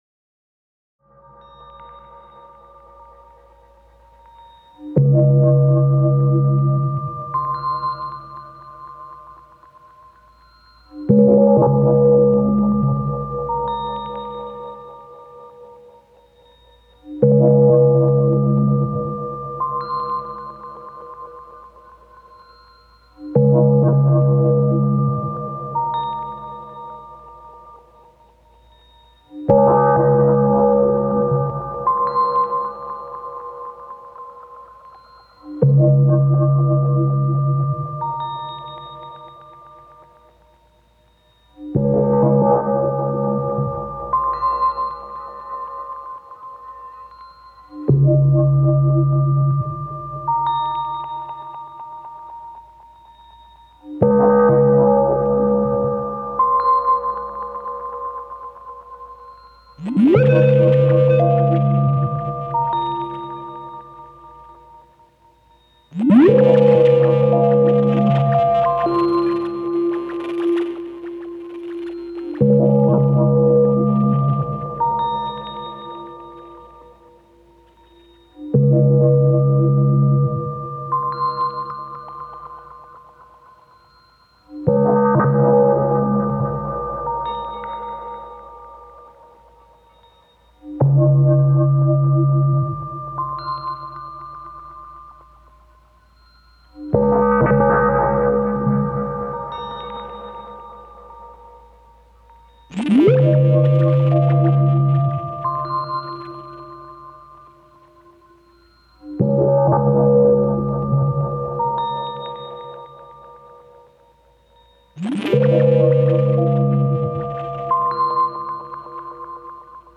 You can send some cc to mute some of the tracks of MnM (maybe modulate the distortion parameter in a subtle way at the same time!?) and even if it sounds quite a simple idea, you’ll get very interesting result because of the semi-controllable gain staging on MnM.
Here I’ve used OT to send some cc messages to MnM: